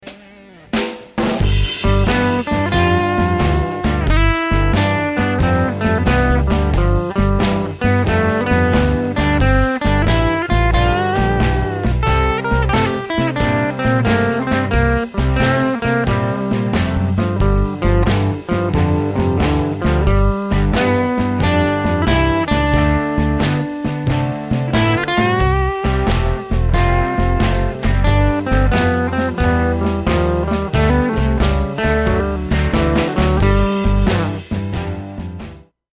Check out the example solo below which uses all 3 modes.
Mixolydian Mode Blues Solo
blues_mixolydian_solo.mp3